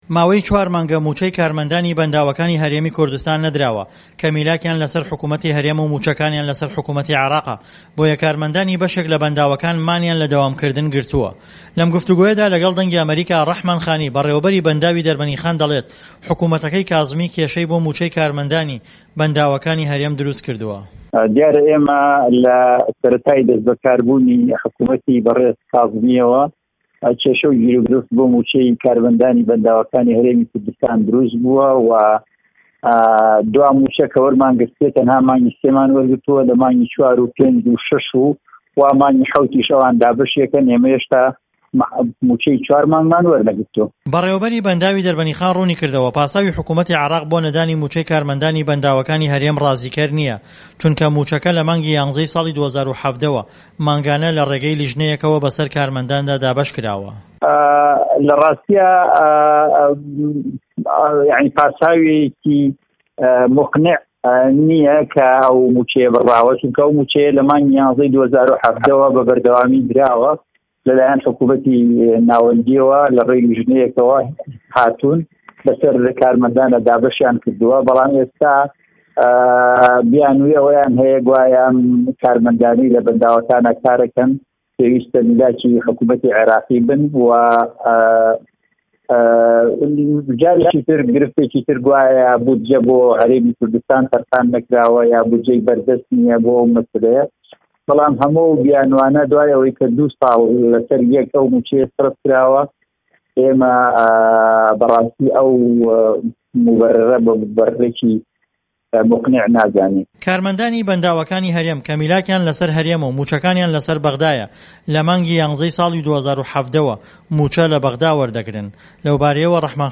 لەم گفتووگۆیەدا لەگەڵ دەنگی ئەمەریکا